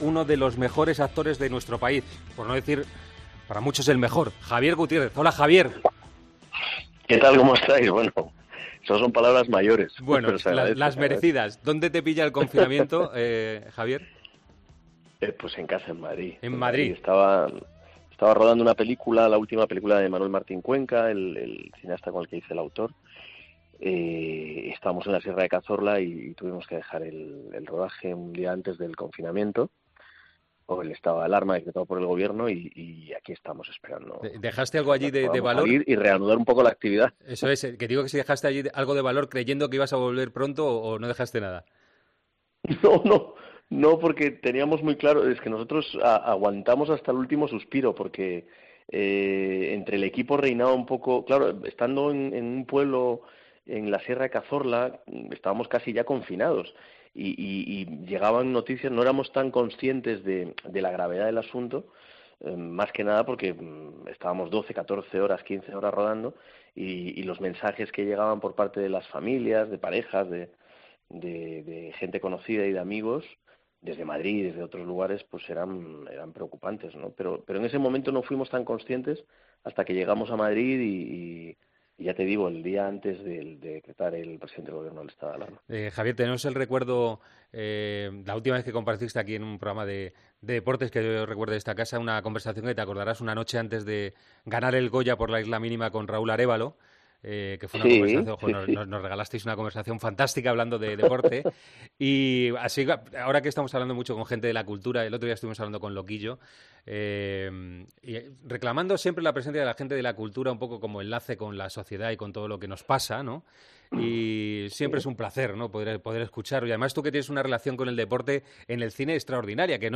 AUDIO: Charlamos con el actor ganador de dos premios Goya y conocemos el lado más solidario de la directora de cine.